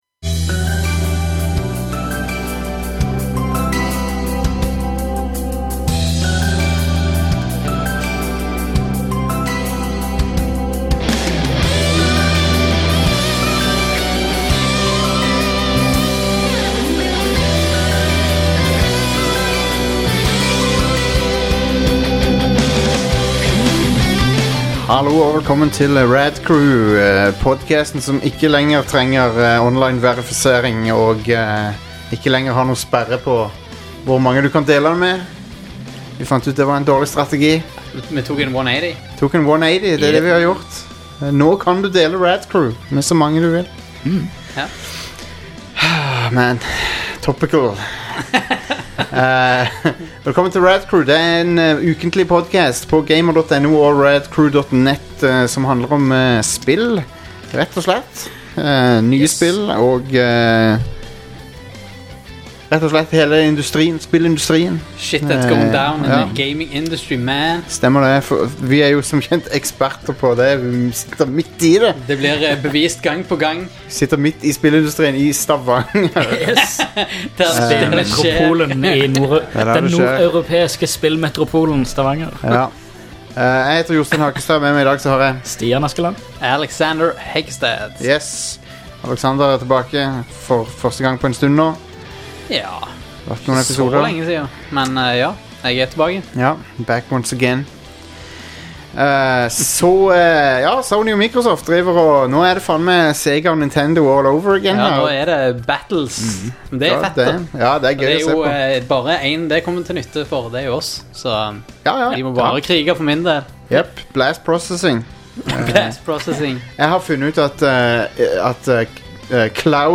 Gutta i studio stiller seg likevel spørsmålet om endringen kom tidsnok eller om Xbox-varemerket allerede er skadet.